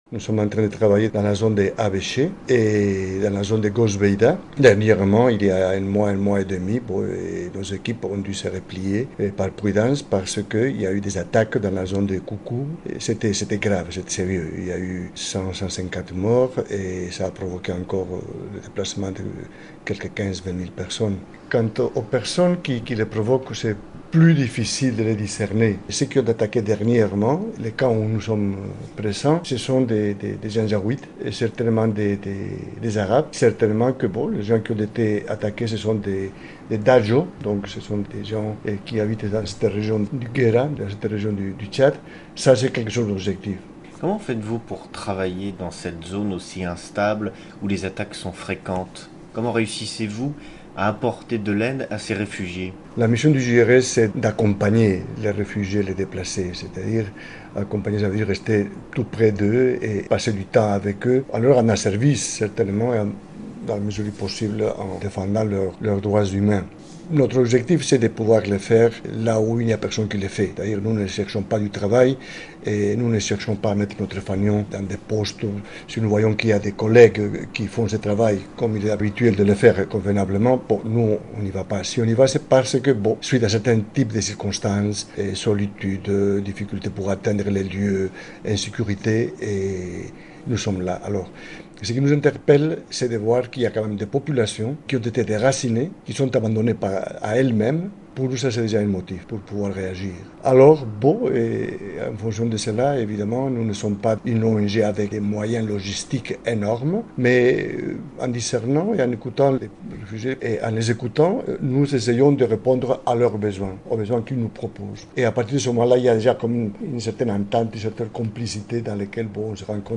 Des propos recueillis